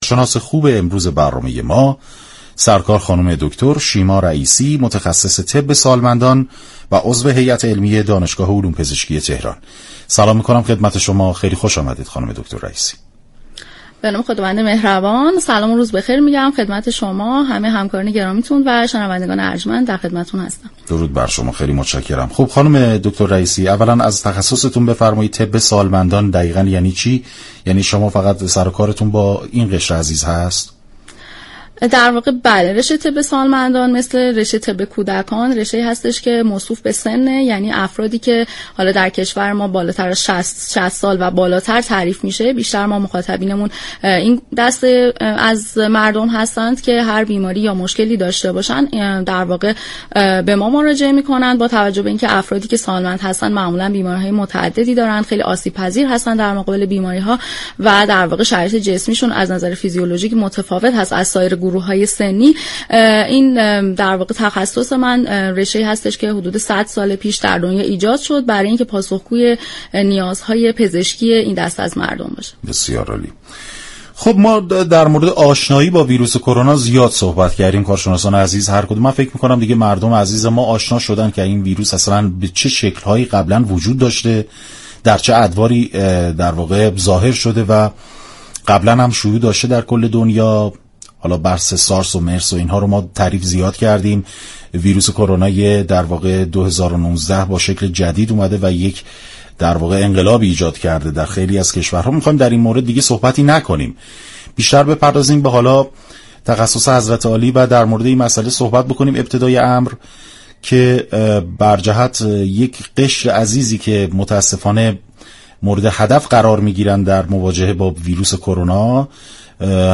شما می توانید از طریق فایل صوتی پیوست شونده بخشی از برنامه مشاور رادیو ورزش كه شامل صحبت های این متخصص درباره كرونا و پاسخگویی به سوالات عموم است؛ باشید. برنامه مشاور با محوریت آگاهی رسانی در خصوص بیماری كرونا ساعت 13 هر روز به مدت 45 دقیقه از شبكه رادیویی ورزش تقدیم شوندگان می شود.